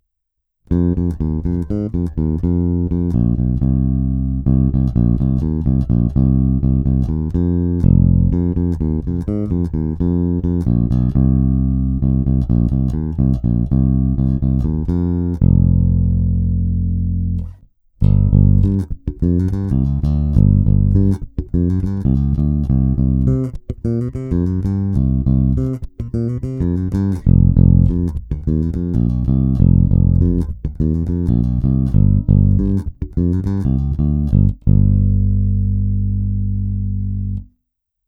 Zvuk je trochu nezařaditelný. Dobrý, pevný, ale charakter Music Manu v něm není ani v náznaku, což jsem upřímně řečeno vlastně vůbec neočekával. Originál Music Man snímač a aktivní elektronika jsou prostě téměř nenahraditelné. Tato aktivní elektronika poskytuje hodně čistý, ničím nezabarvený zvuk, a nutno dodat, že má hodně silný výstup, velký zdvih.